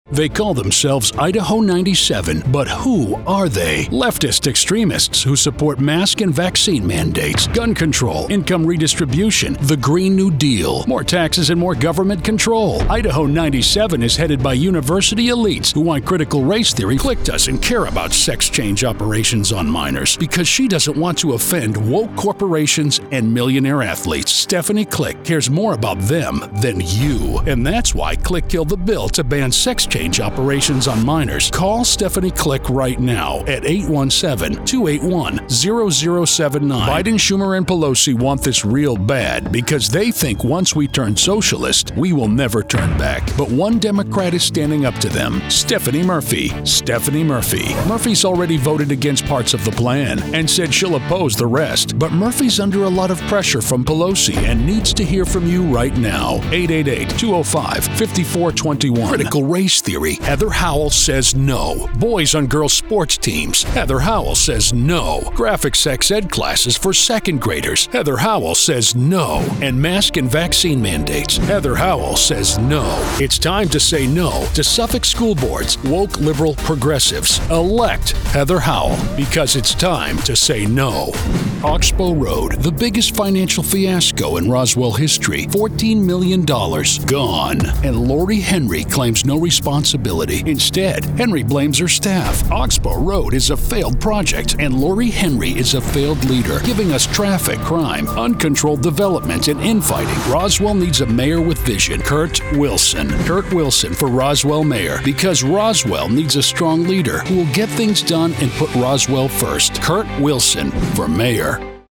Conservative Political Voice Demo